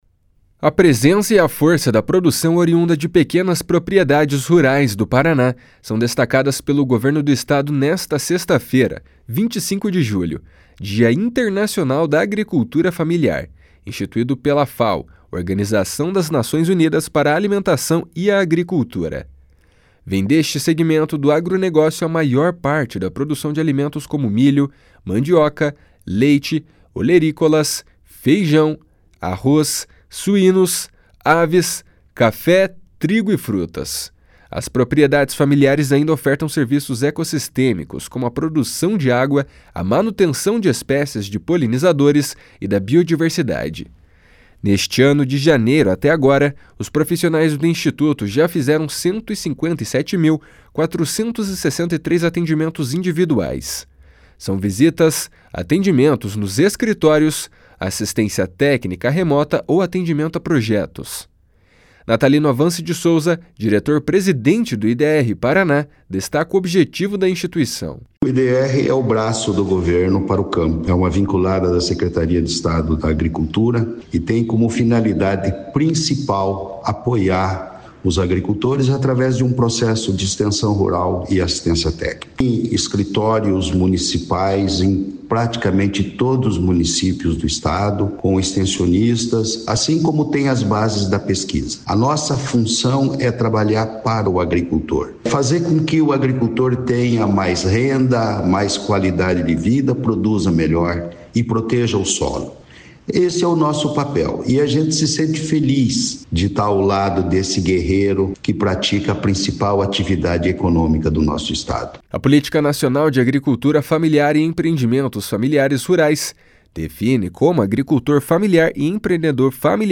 Natalino Avance de Souza, diretor-presidente do IDR-Paraná, destaca o objetivo da instituição. // SONORA NATALINO AVANCE //